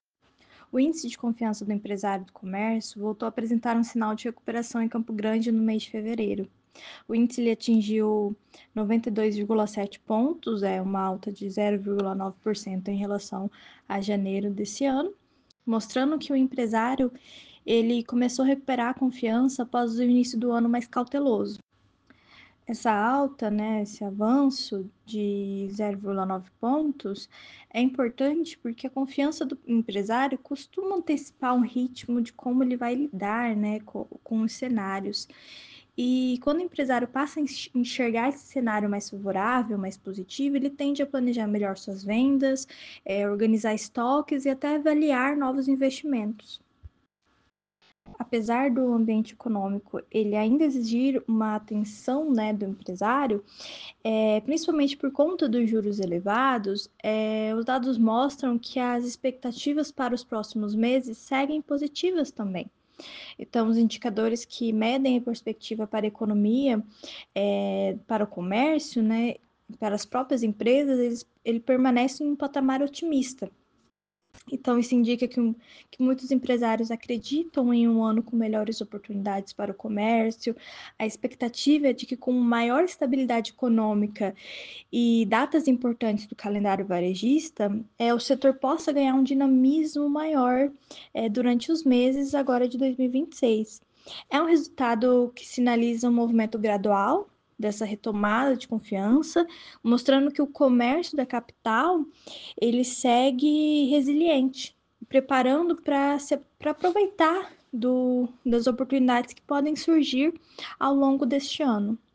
Na entrevista ao “Agora 104” da FM Educativa MS 104.7